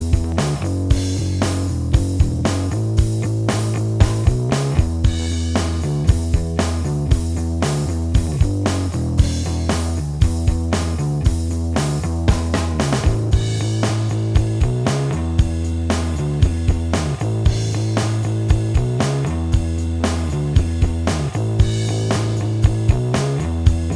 Minus Lead And Solo Indie / Alternative 4:24 Buy £1.50